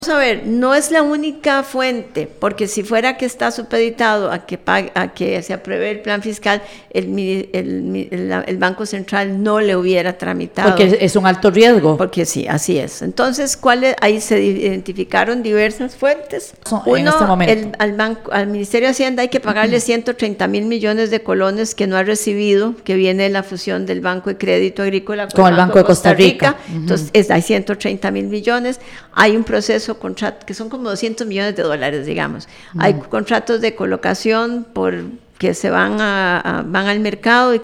La ministra de Hacienda, Rocío Aguilar aseguró hoy en el programa La Lupa, que la decisión de endeudarse con el Banco Central de Costa Rica (BCCR), se tomó porque se quiere evitar el riesgo de la falta de liquidez y prevenir un aumento en las tasas de interés.